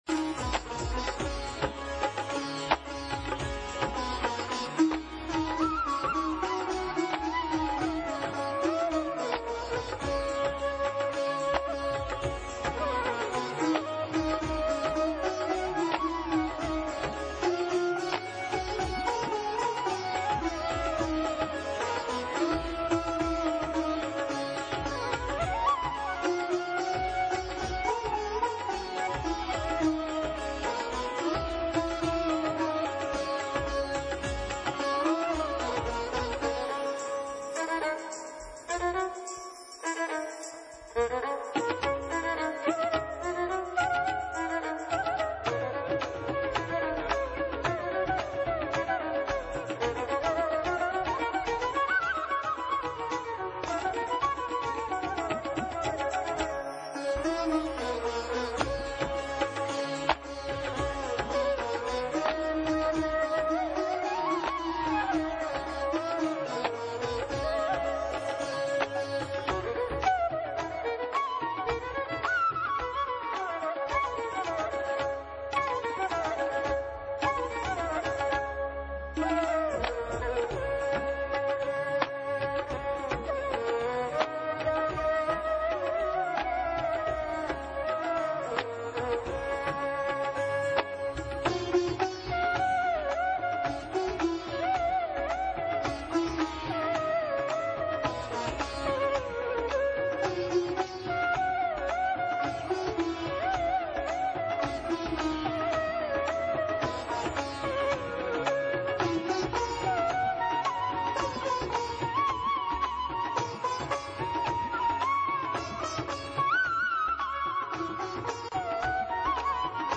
A grand symphony of 1075 Sitarists performing together at Brahmanaad in New Delhi, November 2008.